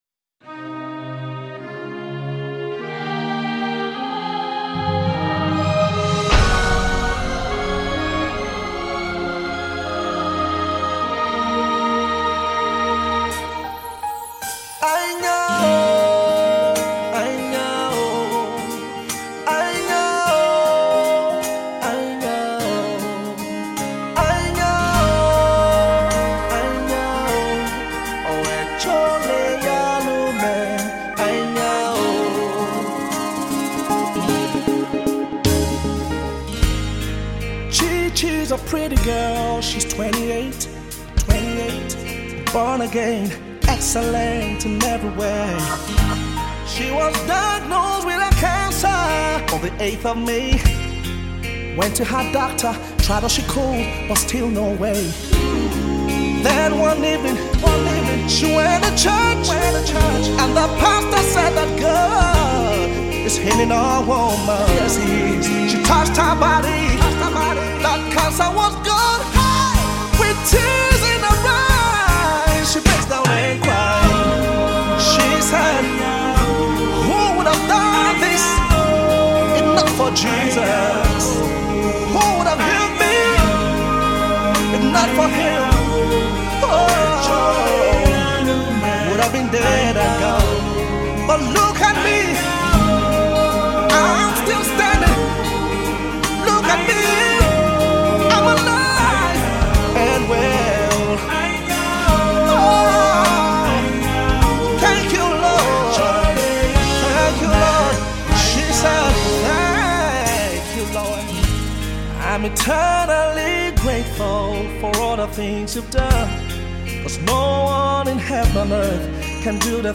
Nigerian gospel artiste
giving us soulful and inspiring music.
they create a melodious tune.